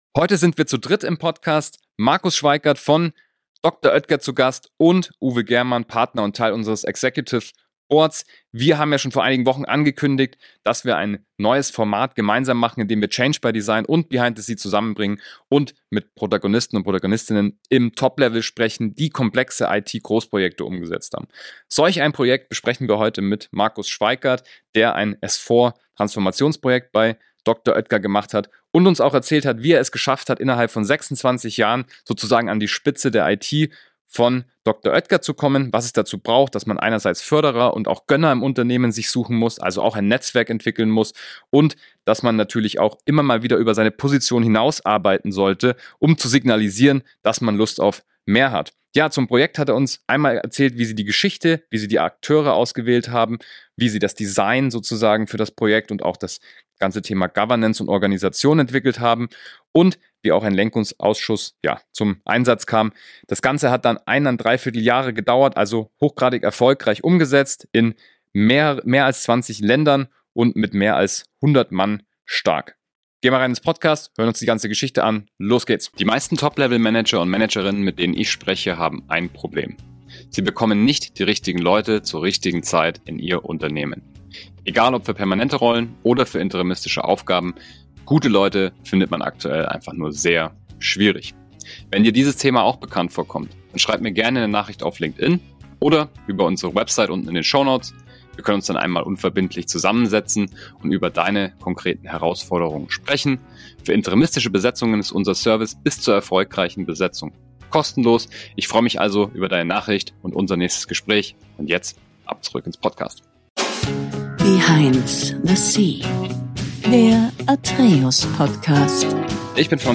Dieses Interview ist ideal für alle, die sich für IT-Projektmanagement und die Zukunft der Unternehmens-IT interessieren.